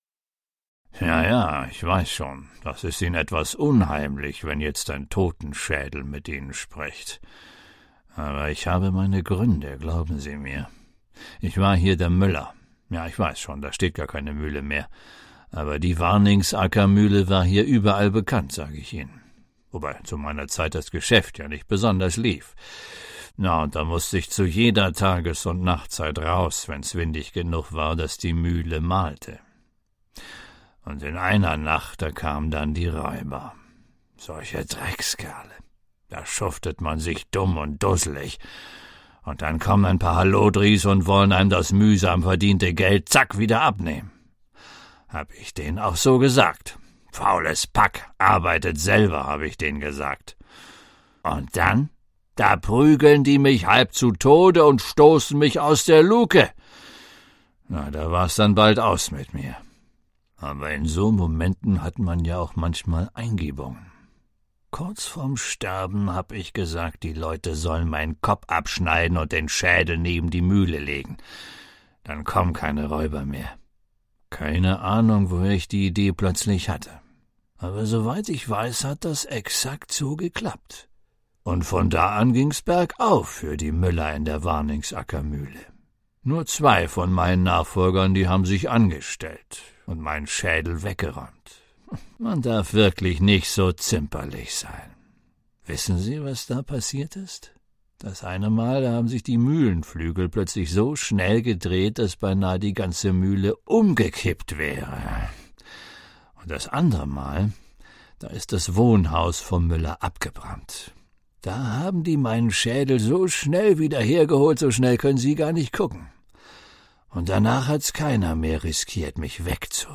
Dort spricht der Totenschädel des Warningsackermüllers mit dem Besucher auf dem Sagenweg. Als Räuber ihn überfielen, verlangte der Müller von ihnen, sie sollten seinen Kopf abschneiden und neben die Mühle legen.
Audiogeschichte